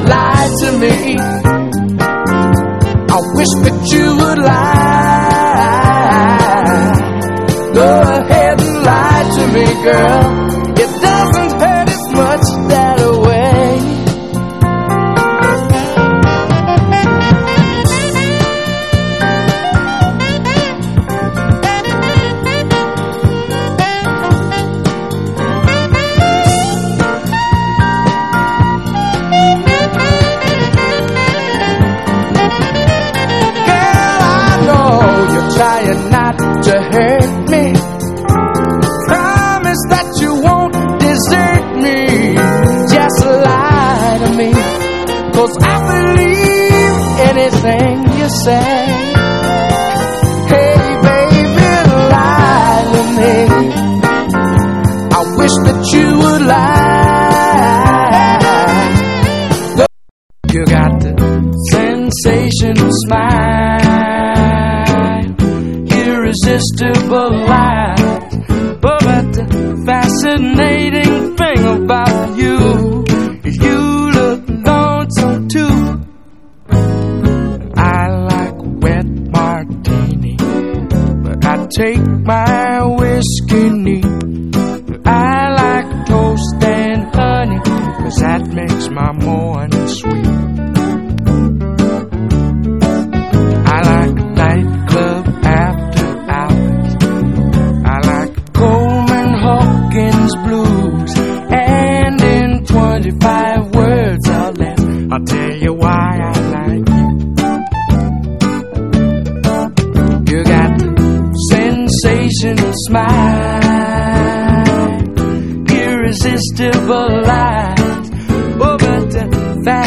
FOLK / FOLK ROCK / 60'S ROCK
アメリカン・フォーク/フォーク・ロックの古典をコンパイル！